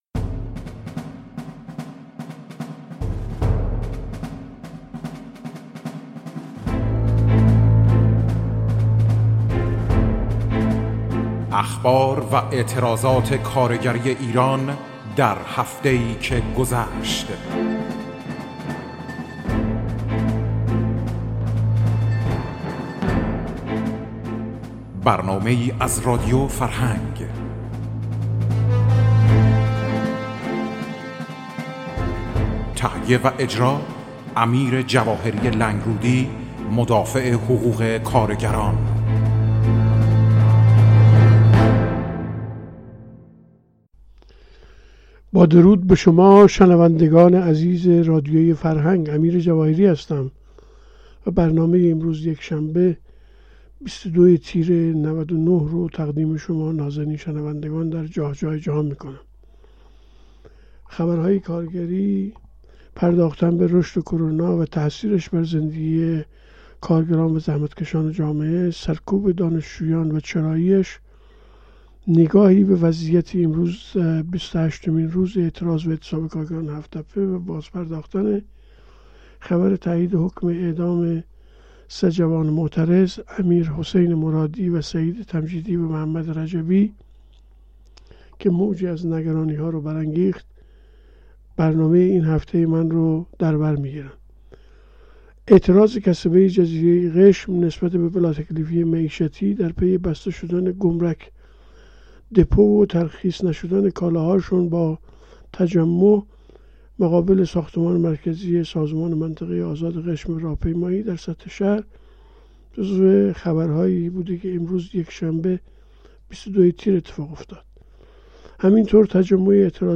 اخبار و اعتراضات کارگری امروز یکشنبه ٢٢ تیر ماه ۱۳۹٩ تهیه و اجراء از